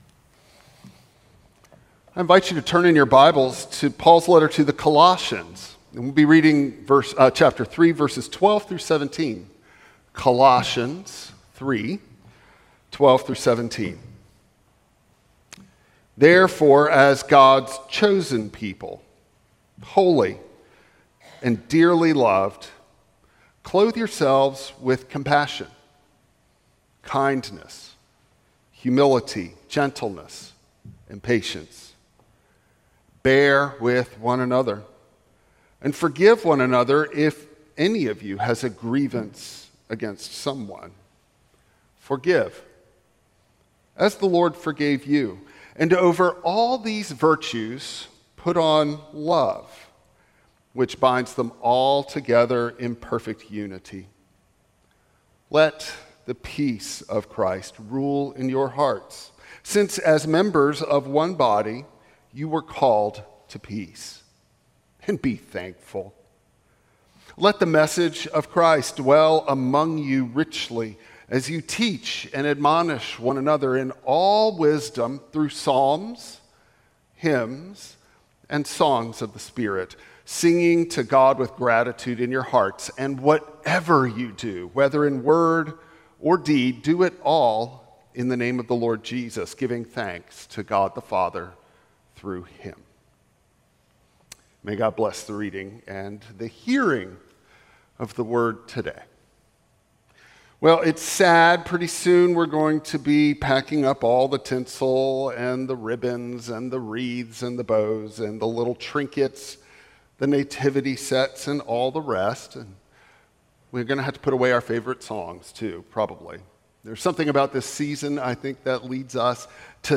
Colossians 3:12-17 Service Type: Traditional Service God’s salvation transforms grief into gratitude.